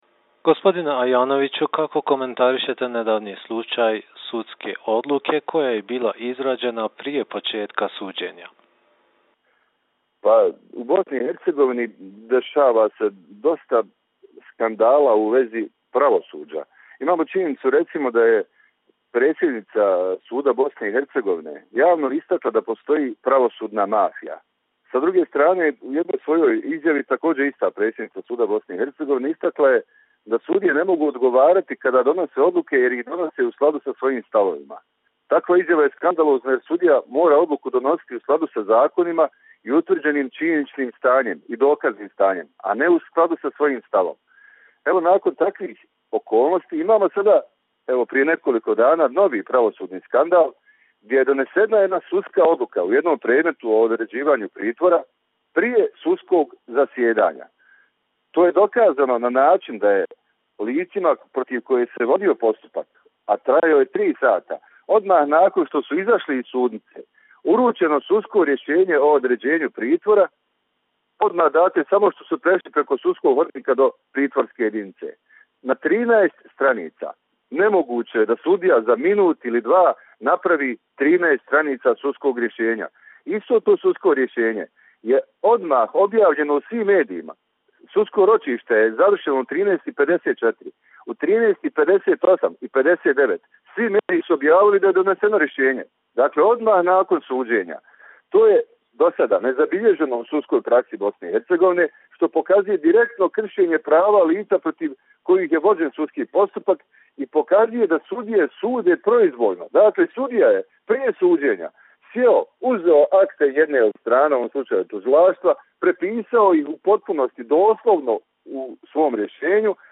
Tonske izjave